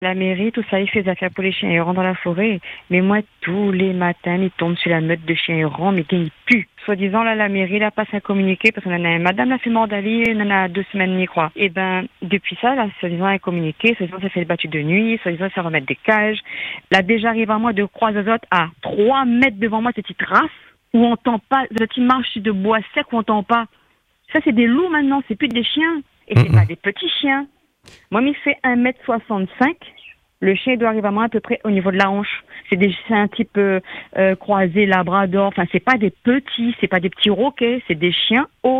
Une auditrice, habituée à s’y rendre régulièrement, témoigne de son inquiétude.